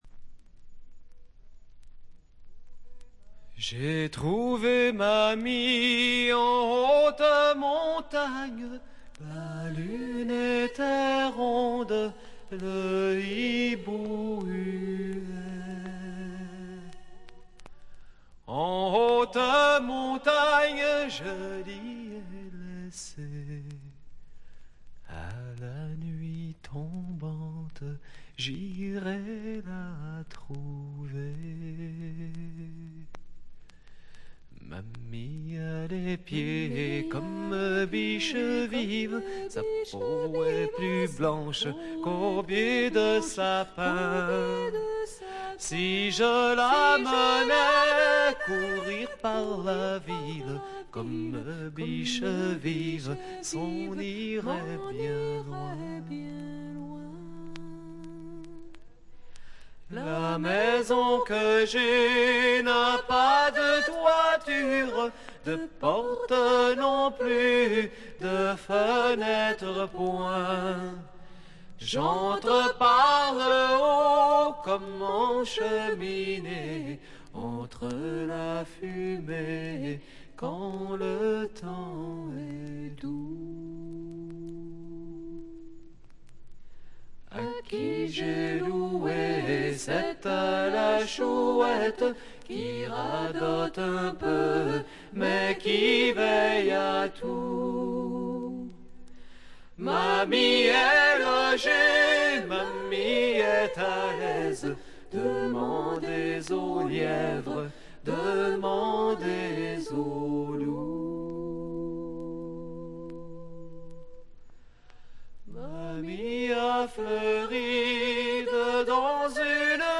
静音部で軽微なバックグラウンドノイズやチリプチが少々認められる程度。
カナディアン・プログレッシヴ・ドリーミー・フォークの名作。
試聴曲は現品からの取り込み音源です。